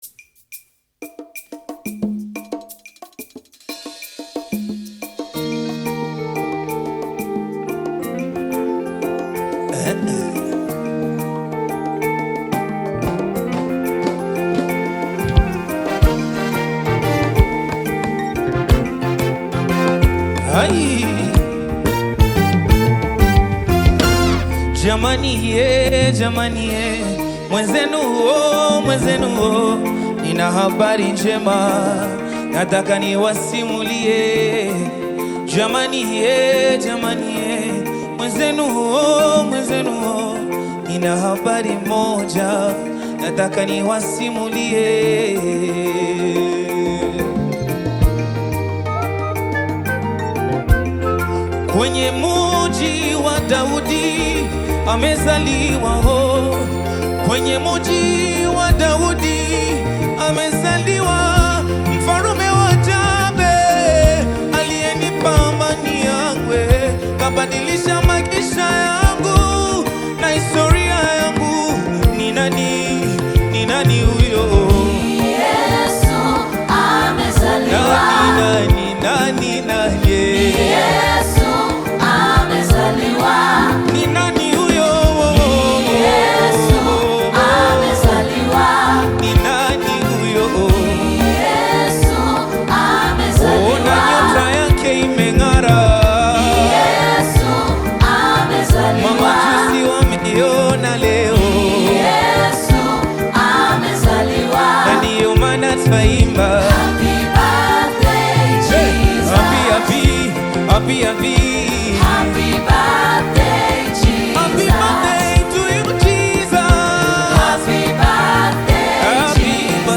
Tanzanian gospel music